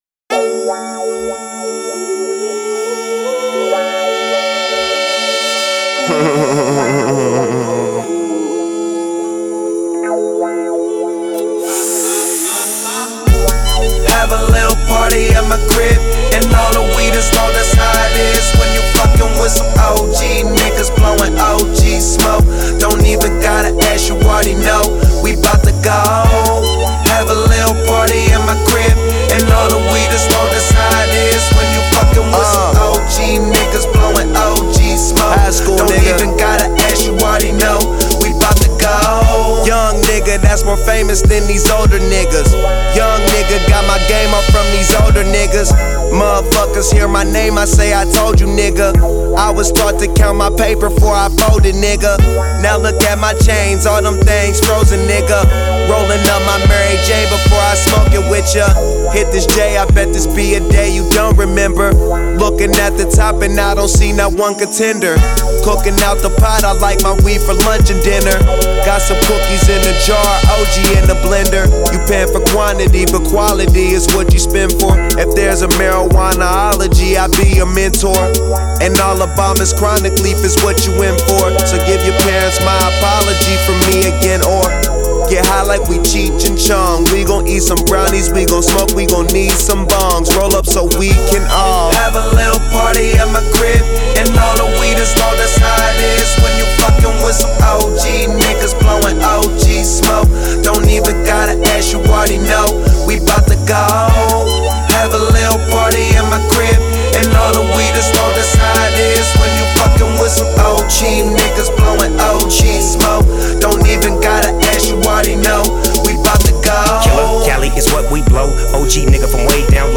space-age funk sample